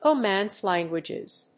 アクセントRómance lánguages